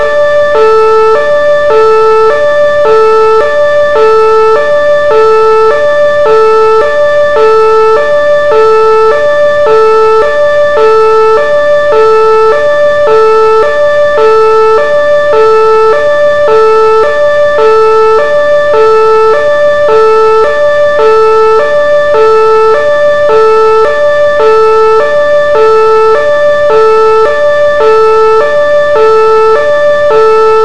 別途、サイレン音が必要になりますが、お持ちでない方は
siren.wav